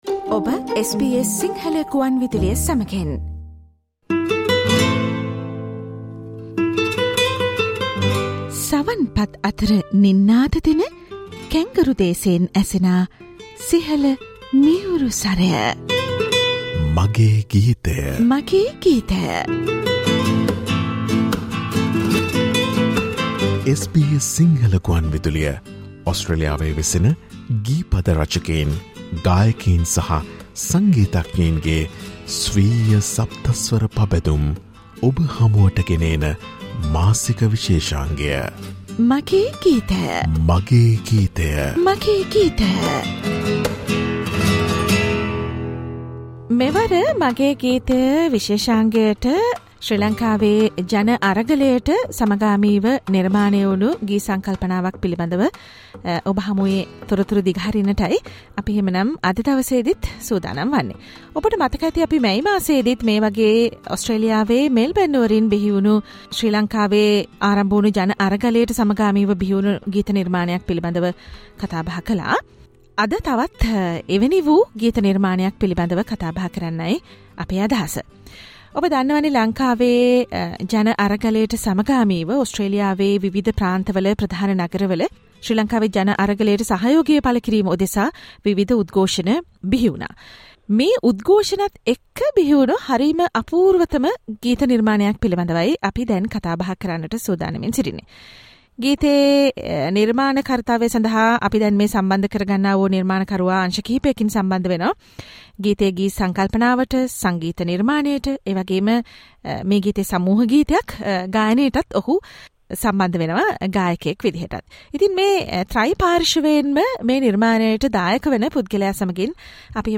SBS Sinhala ‘My song’ monthly musical program: Perata Enu Lak Puthune song from Melbourne.
ගායනය - සමුහ